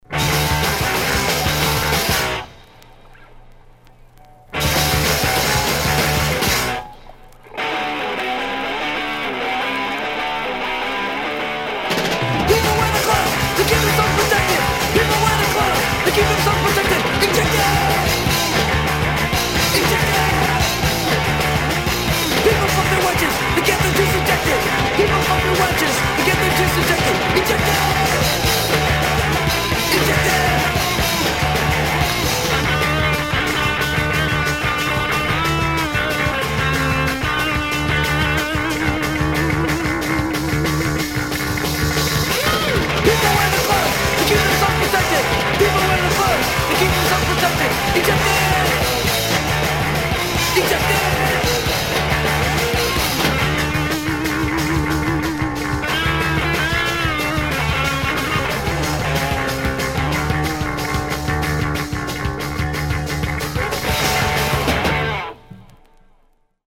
great reggae-tinged hardcore track
classic old school HC track